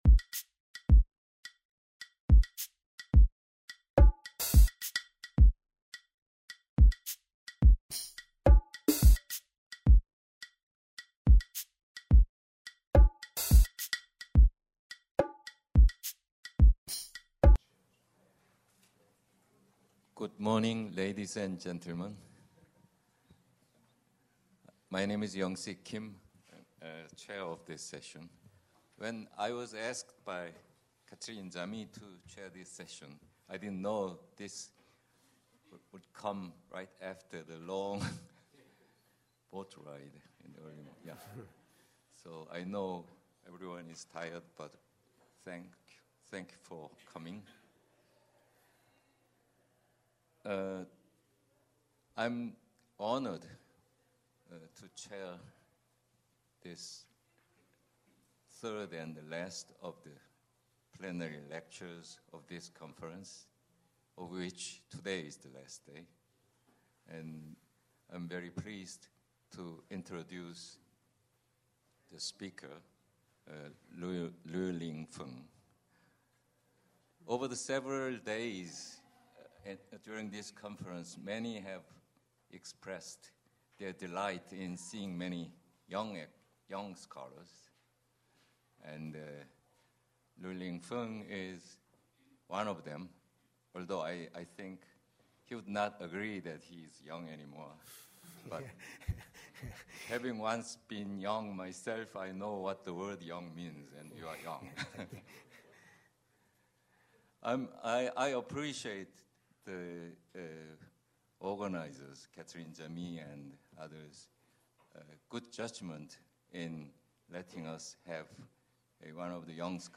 Plenary Lecture Chair